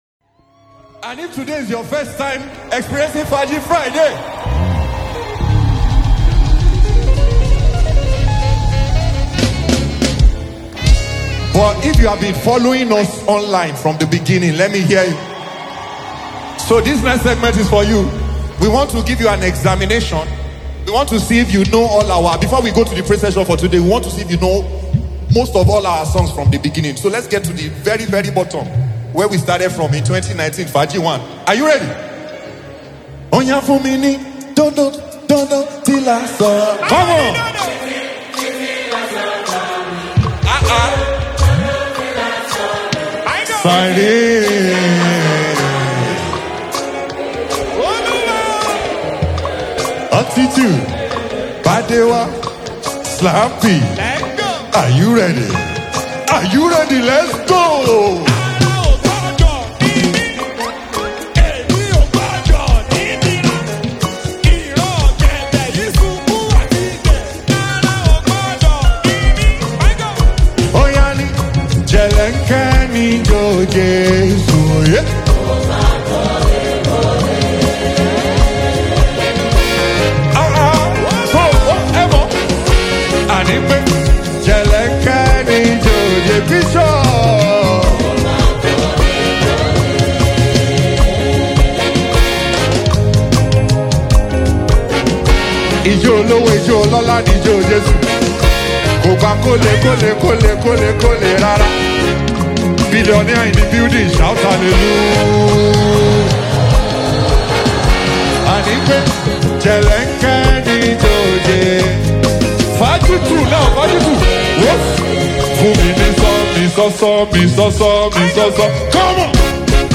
powerful instrumentation and harmony